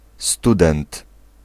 Ääntäminen
Ääntäminen Tuntematon aksentti: IPA: /stɵˈdɛnt/ IPA: /stu'dent/ Haettu sana löytyi näillä lähdekielillä: ruotsi Käännös Ääninäyte 1. studentka {f} 2. student {m} Artikkeli: en .